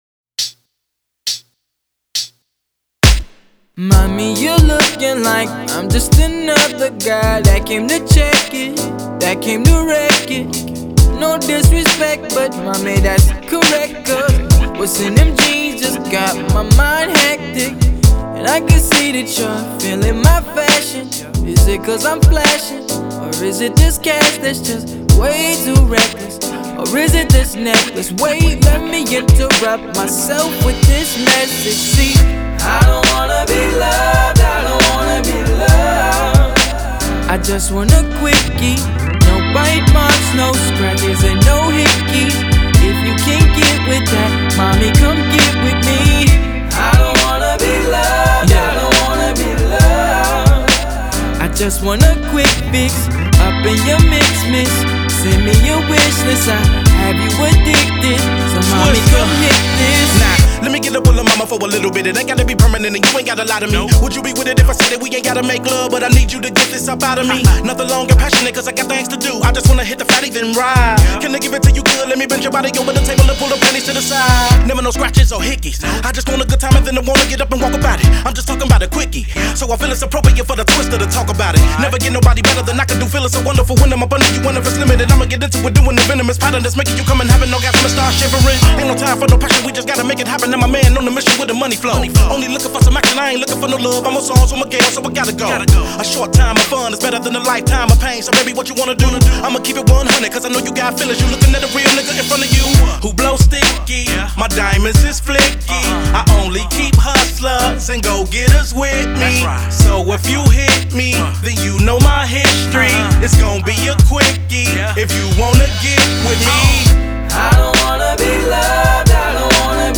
official remix
and when it comes to this fast rap